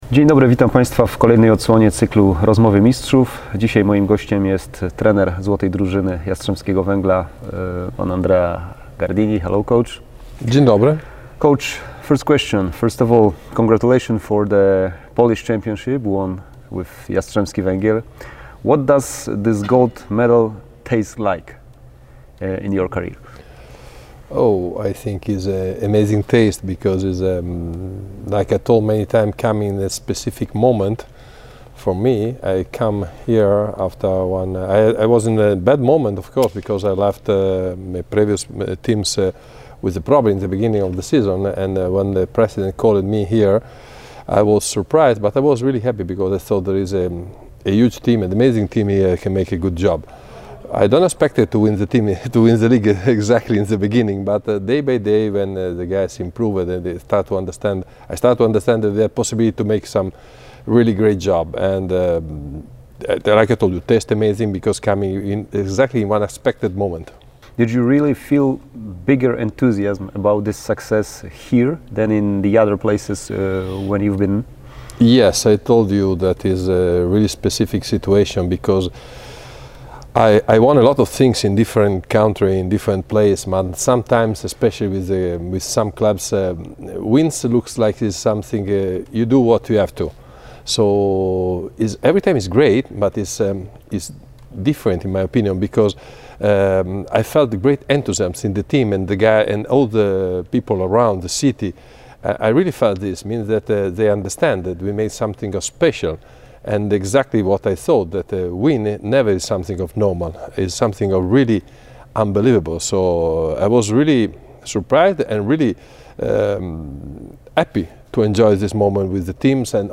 Rozmowa z trenerem Jastrzębskiego Węgla